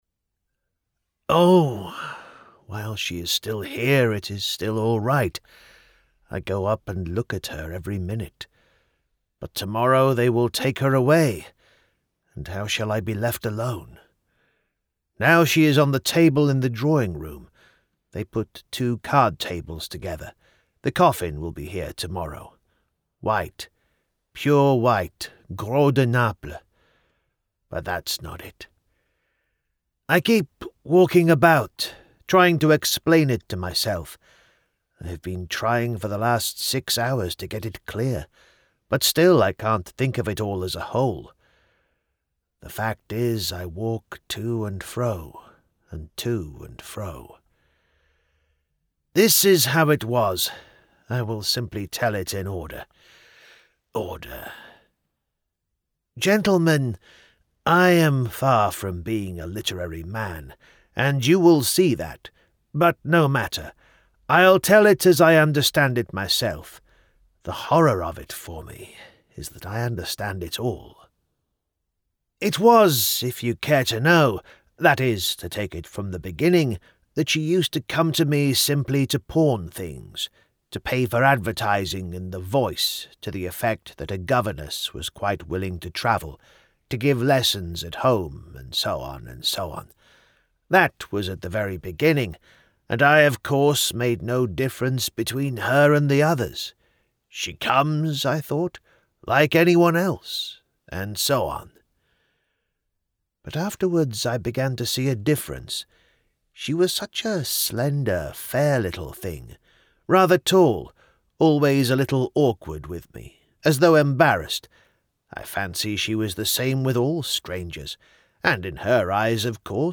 A Selection of Audiobook Samples
(Classics, fiction)